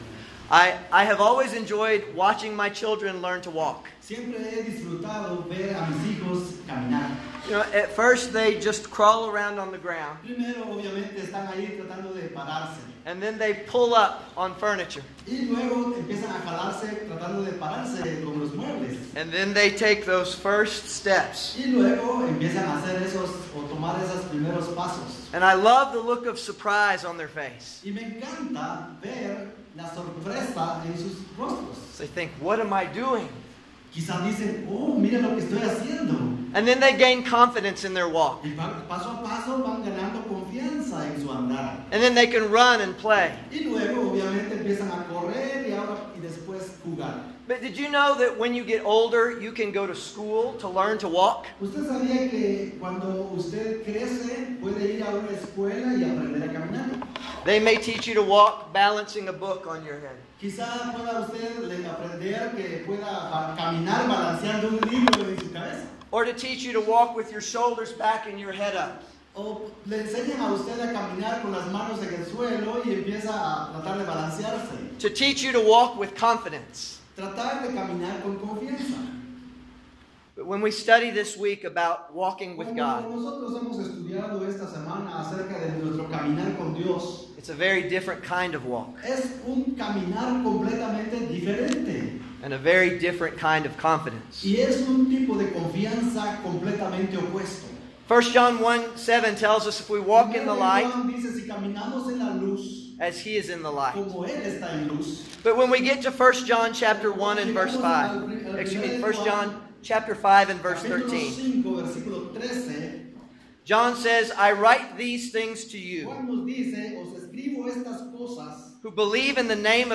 by admin | Apr 28, 2019 | ITL Lectureship 2019, Lectureships/Seminarios, Sermon